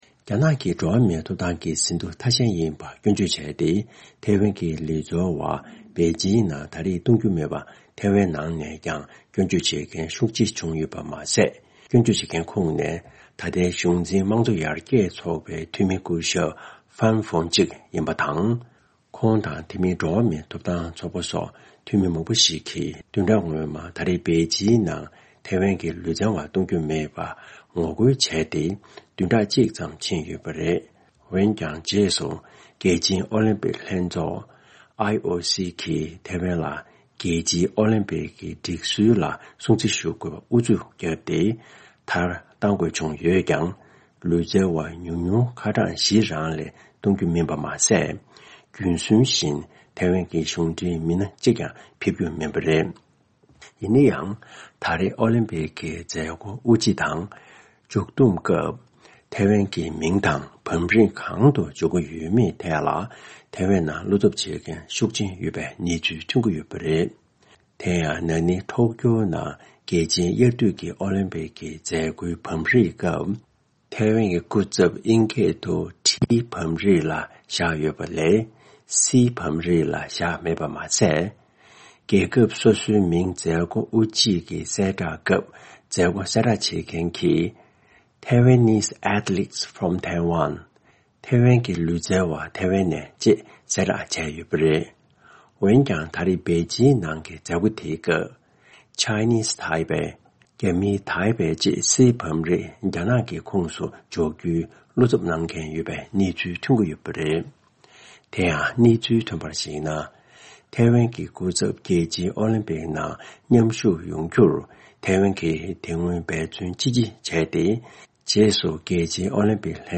སྙན་སྒྲོན་ཞུ་གནང་གི་རེད།།